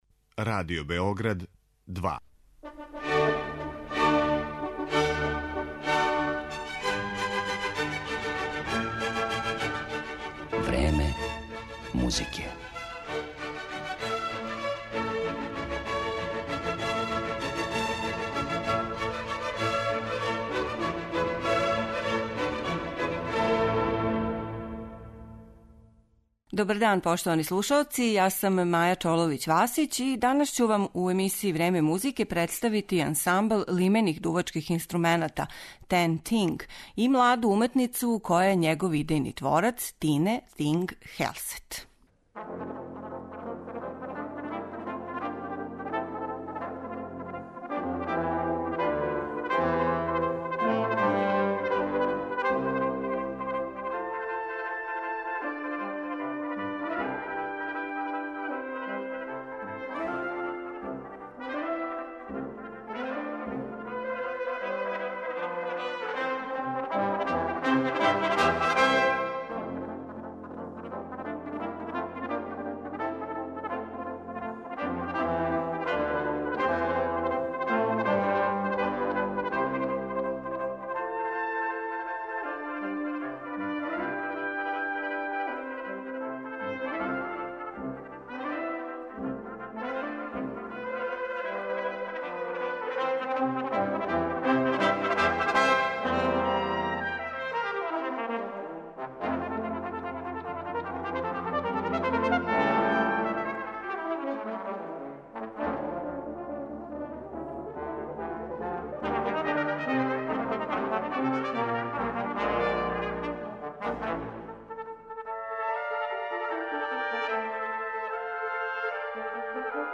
Ансамбл лимених дувачких инструмената
виртуоз на труби.
Овај десеточлани, искључиво женски, ансамбл